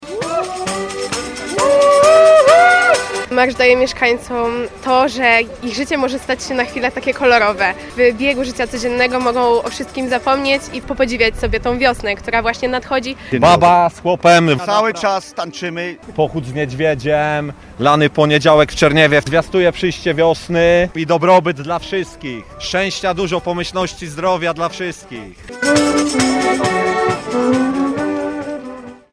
Głośny i kolorowy drugi dzień świąt w Czerniejewie koło Gniezna. Tam tradycyjnie przez miasteczko przeszedł barwny korowód przebierańców.
Z kapelą ludową diabły, kominiarze i dziad z babą prowadzili słomianego niedźwiedzia symbolizującego zimę.